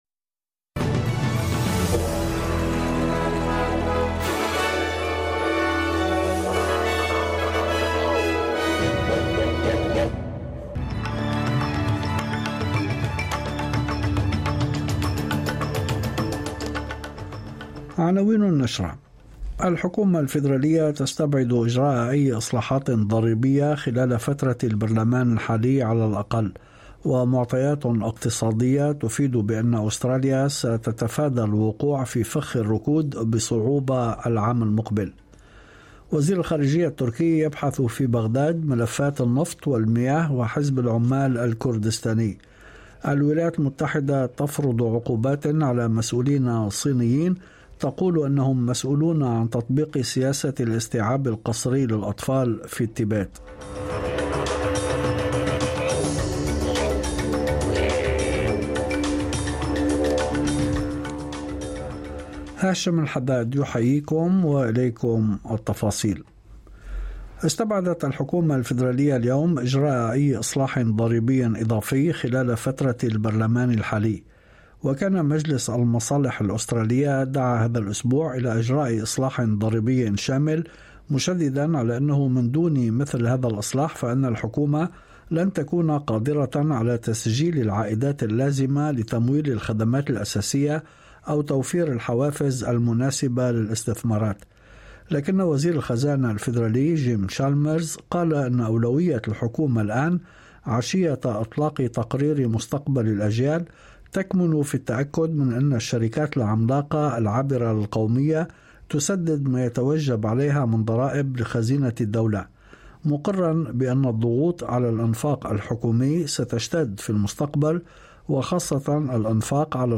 نشرة أخبار المساء 23/8/2023